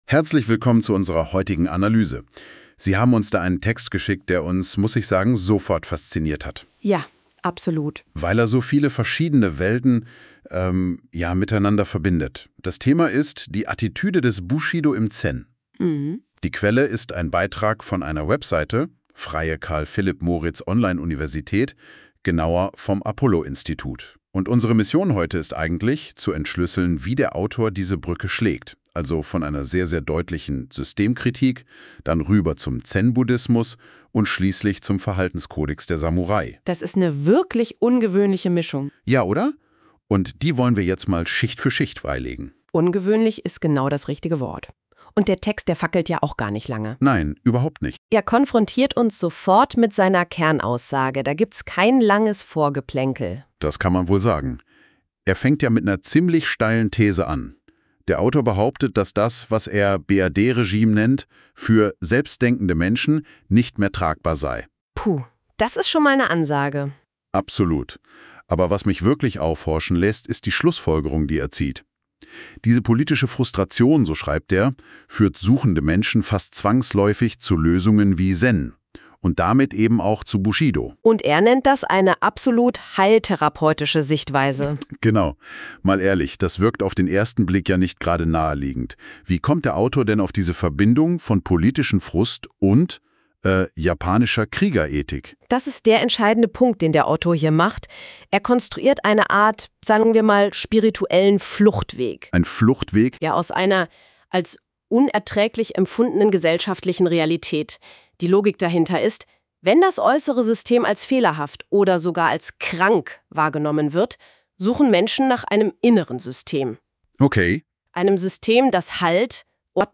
Podcast des poetologischen Studien-Textes im Stil einer Rezension Bushido als Therapie gegen politischen Frust